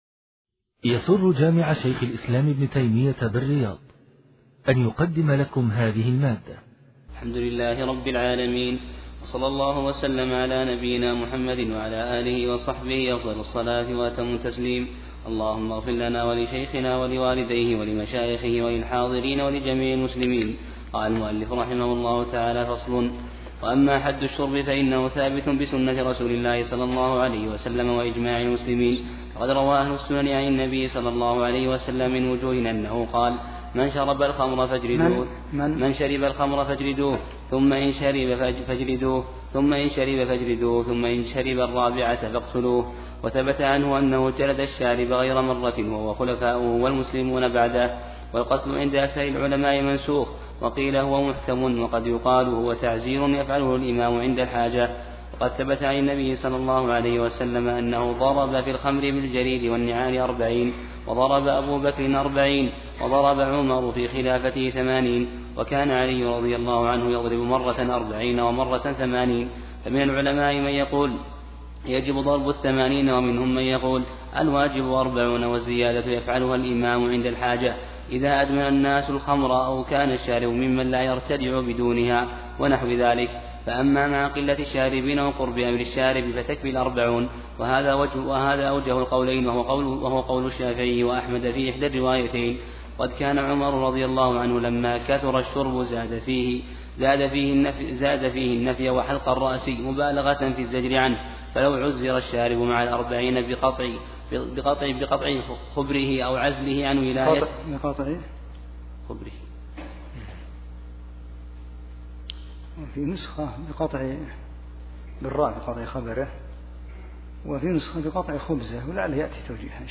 6 - الدرس السادس